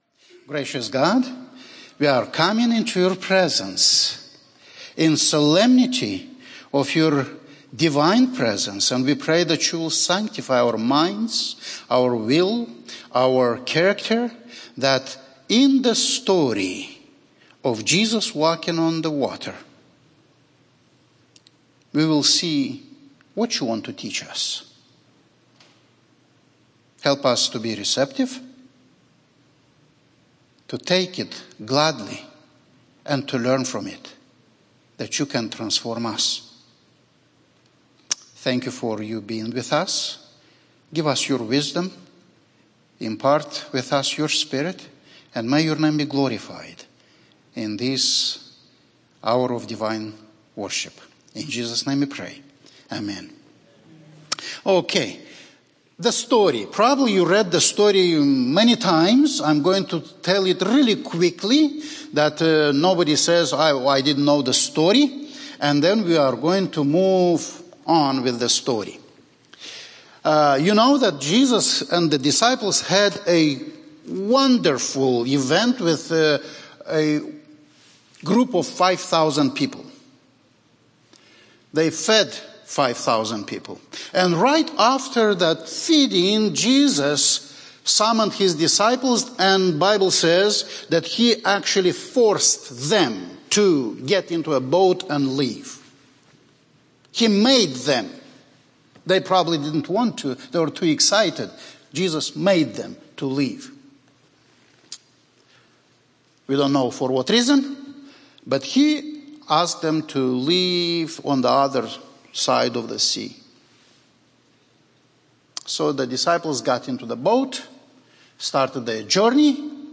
Walking On Water (Panel Discussion)